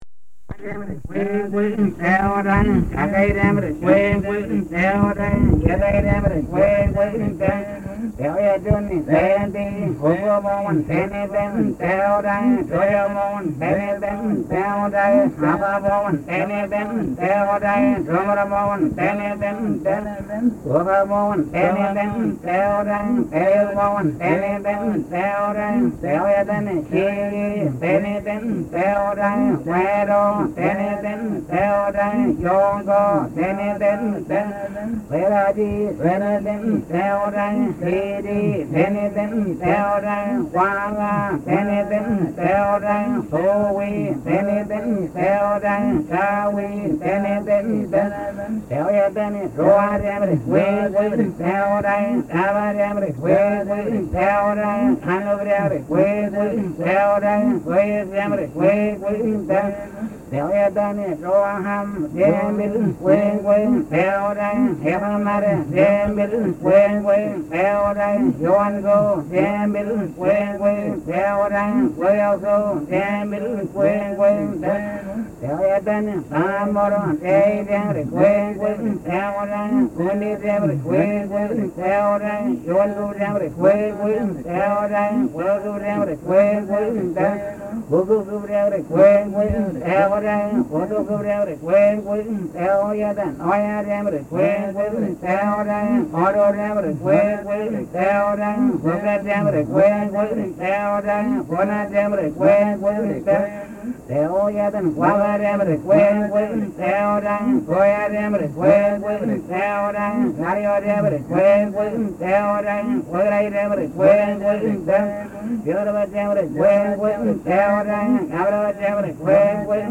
U'wa group chant
U'wa group chant from the Andes in north-east Colombia.
From the sound collections of the Pitt Rivers Museum, University of Oxford, being from a collection of reel-to-reel recordings of U'wa songs and stories made by anthropologist